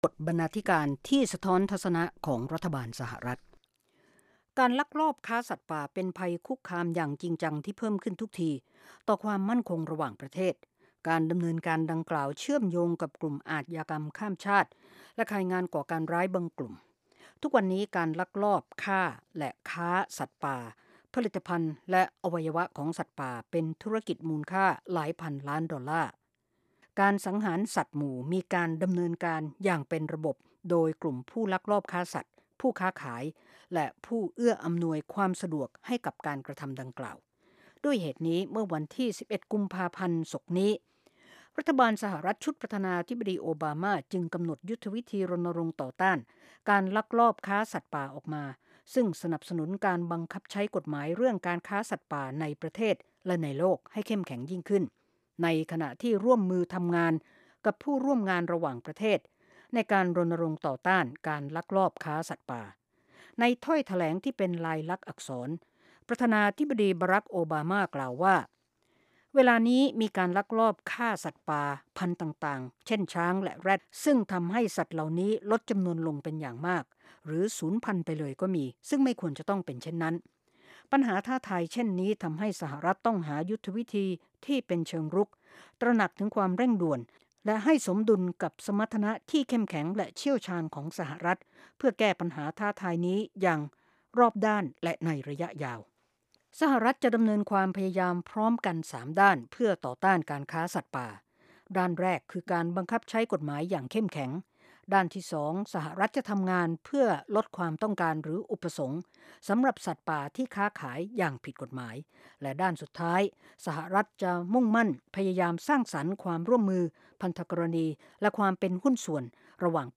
วาไรตี้โชว์ ฟังสบายๆ สำหรับวันหยุดสุดสัปดาห์ เริ่มด้วยการประมวลข่าวในรอบสัปดาห์ รายงานเกี่ยวกับชุมชนไทยในอเมริกา หรือเรื่องน่ารู้ต่างๆ ส่งท้ายด้วยรายการบันเทิง วิจารณ์ภาพยนตร์และเพลง MP3